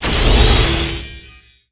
Magic_SpellShield08.wav